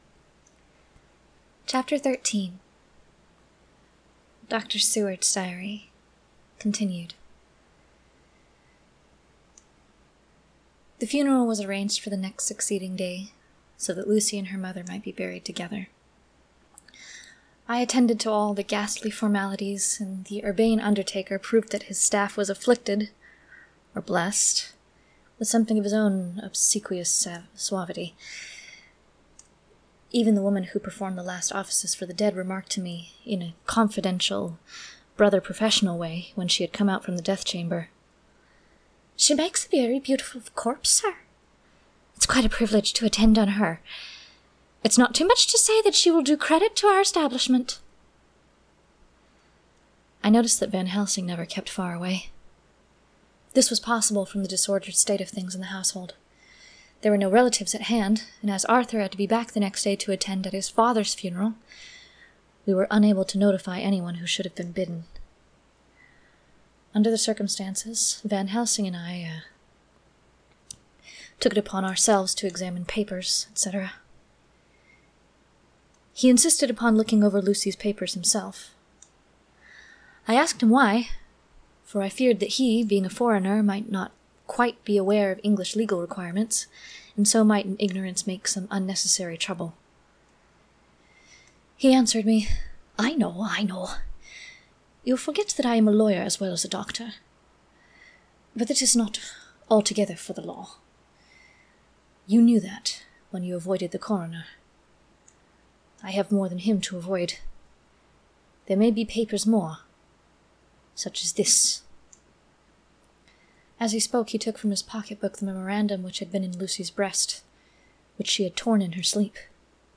Recorded live weekly on Tuesdays at 3:30PST on Discord!